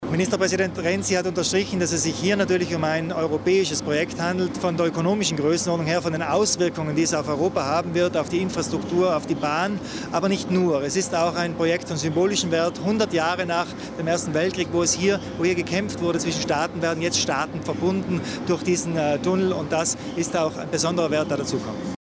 Landeshauptmann Kompatscher äußert sich zu den lobenden Worten zum Brennerbasistunnel von Ministerpräsident Renzi